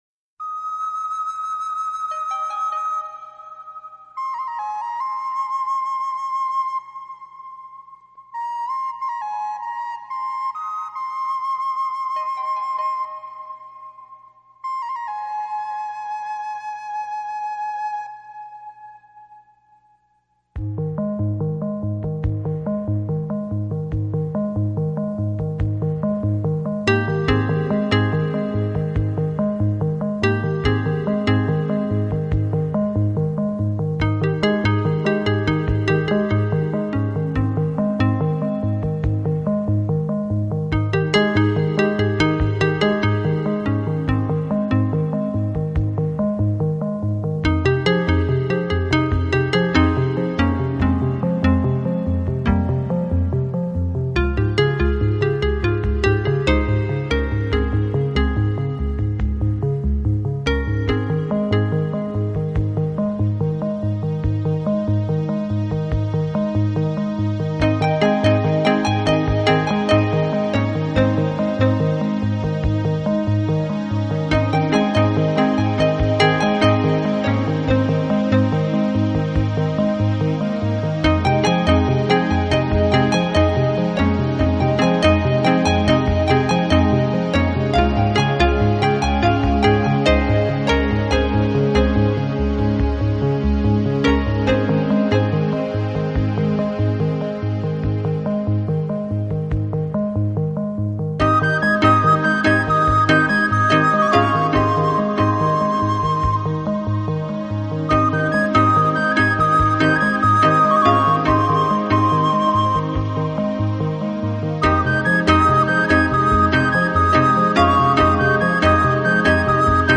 音乐类型：Classical Crossover/Vocal/Instrumental/Easy Listening
CD 2 - Relaxation